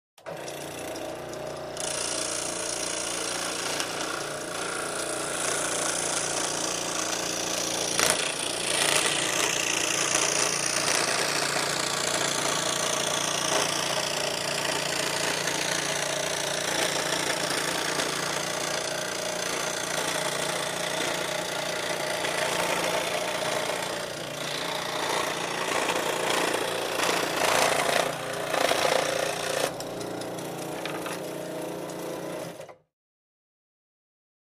in_scrollsaw_sawing_01_hpx
Scroll saw idles, cuts thin wood and shuts off.